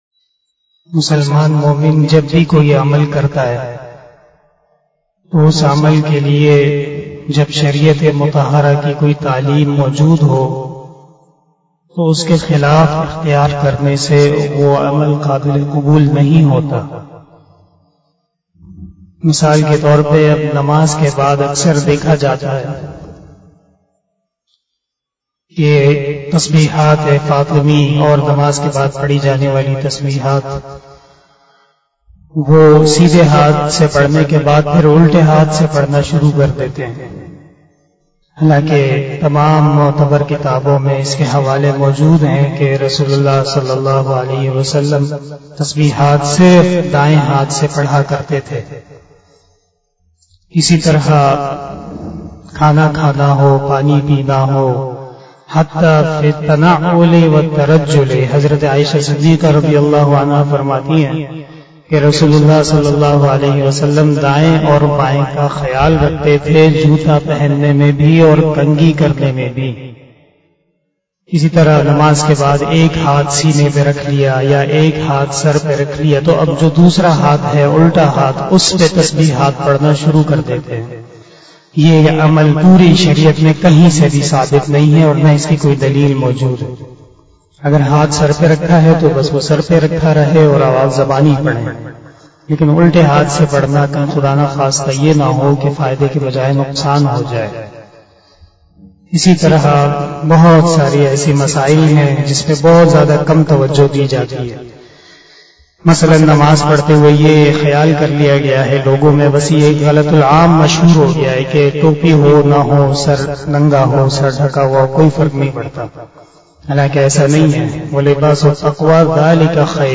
045 After Asar Namaz Bayan 20 August 2021 (11 Muharram 1443HJ) Friday